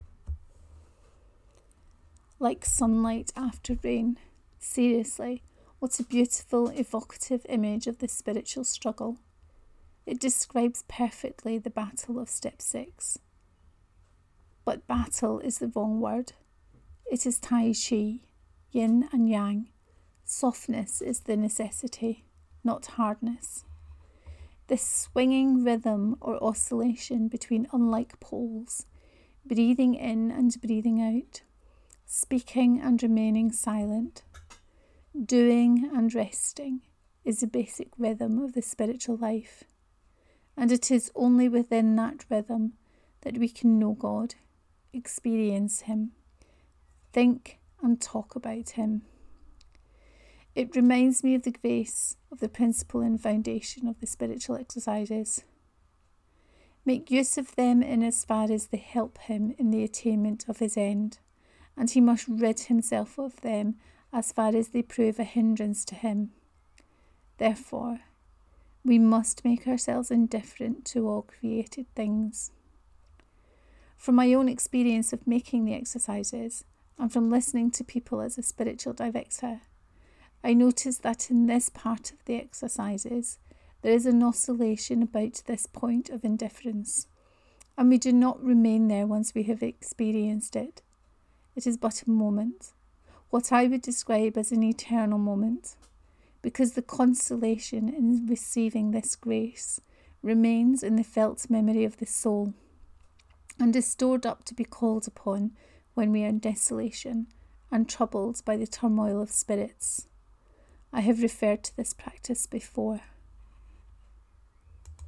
Were entirely ready…3: Reading of this post